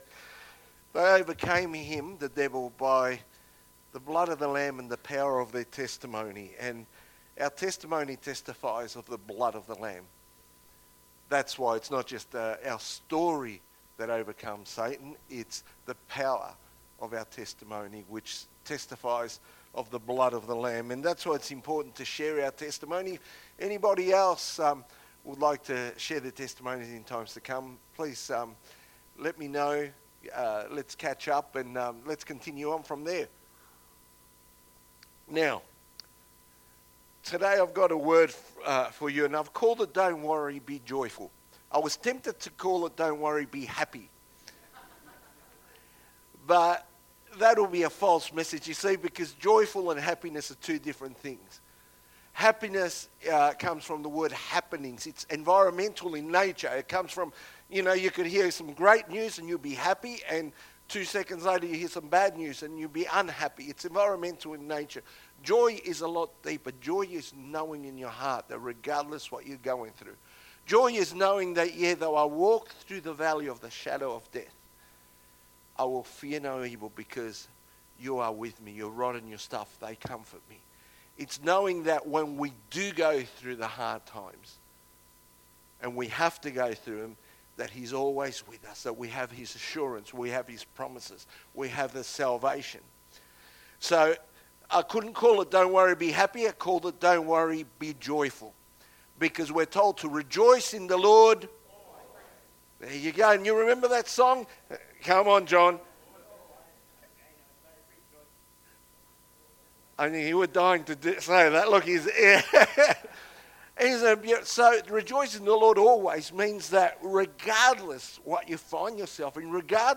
Sermons | Wonthaggi Baptist Church